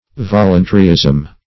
voluntaryism - definition of voluntaryism - synonyms, pronunciation, spelling from Free Dictionary
Search Result for " voluntaryism" : The Collaborative International Dictionary of English v.0.48: Voluntaryism \Vol"un*ta*ry*ism\, n. (Eccl.)